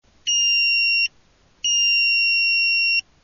定格電圧５Ｖｄｃの電子ブザーが搭載されていますが、この電子ブザーは電源出力ラインに接続されています。
今回は約１．３Ｖ（電流０．１ｍＡ以下）を印加することでそれなりの音量でブザー音を発生できました。
ブザー音のサンプル音の「